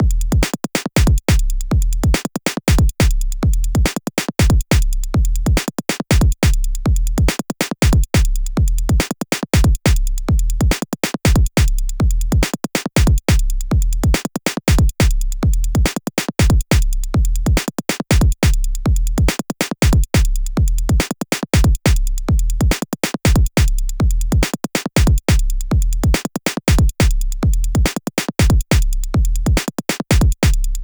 ご用意したのは、ステレオの一般的なループ音源と、POシリーズに同期をして楽しめるクリック入りのシンクモードのループ音源です。
アナログライクなドラムマシン
BPM 140（TECHNOモード）